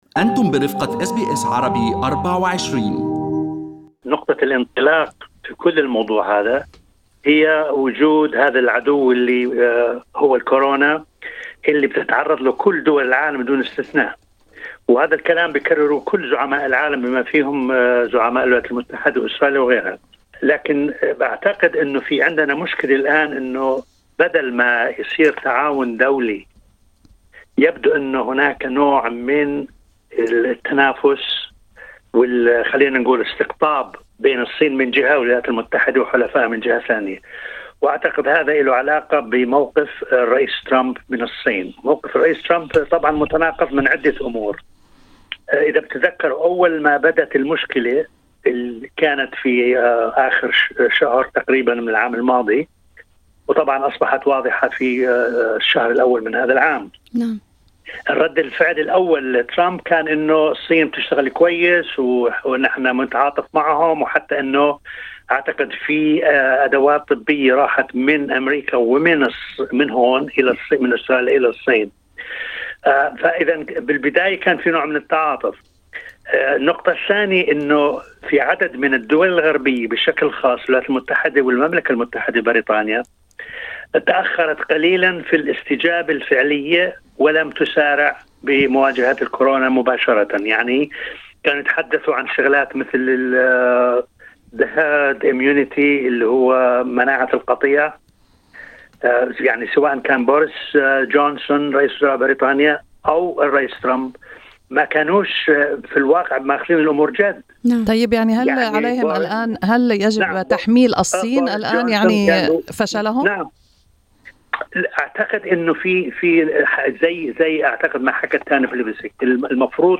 وفي حديث مع SBS Arabic24